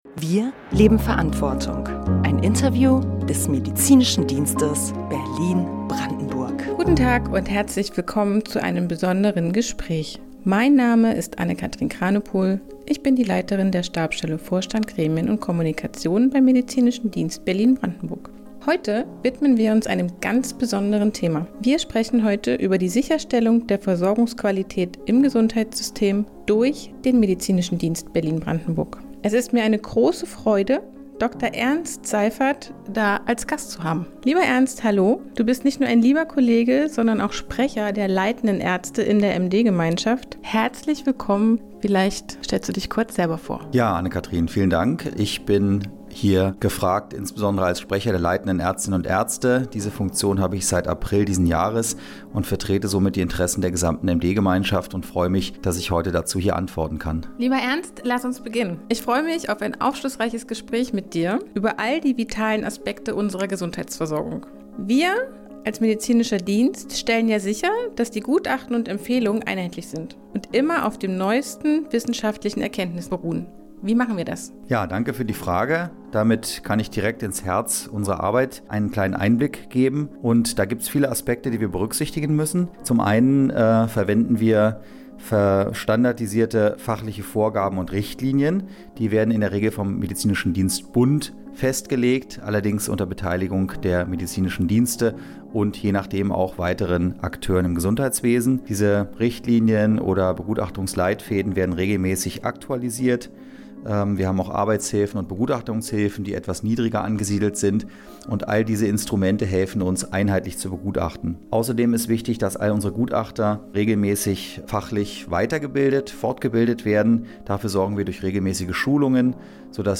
Hier können Sie das vollständige Interview anhören.